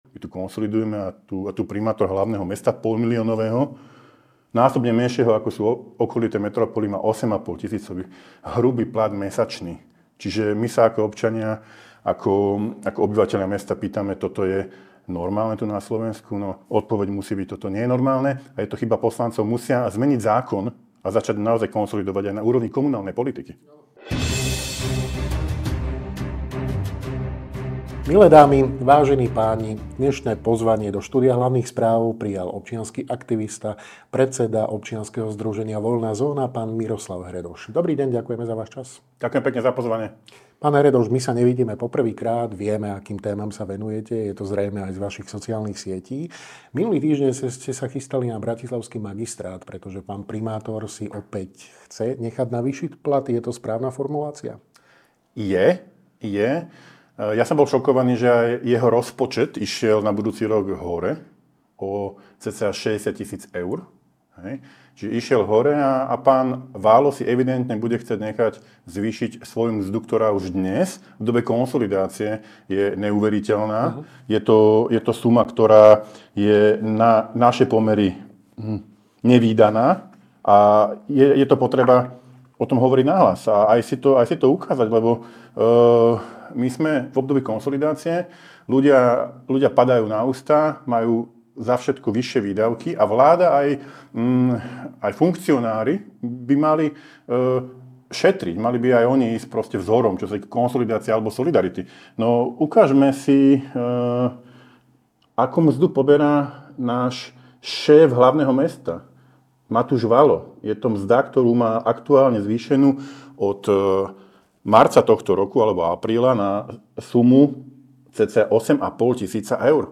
NAŽIVO